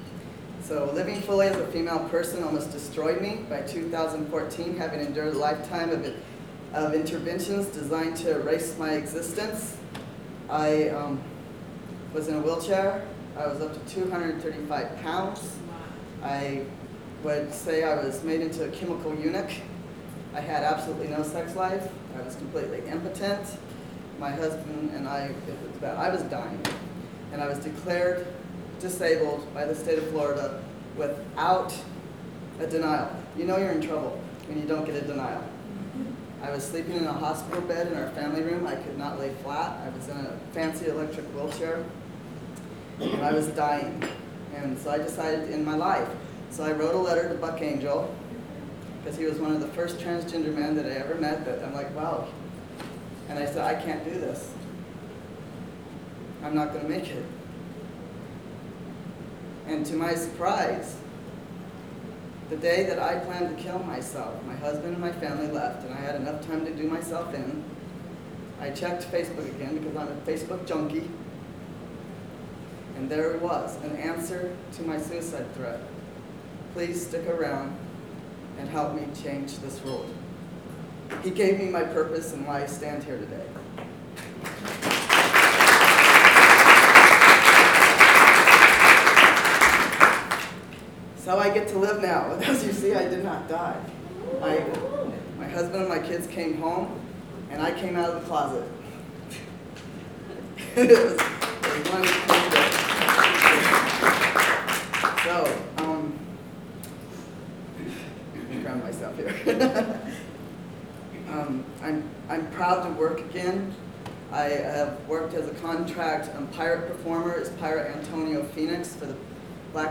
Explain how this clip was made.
Below is an audio clip, from the PFLAG National Conference in October 2017, where I share why I didn’t end my life and why I decide to save myself to help change this world. Audio Clip from PFLAG National Conference in Portland, October 2017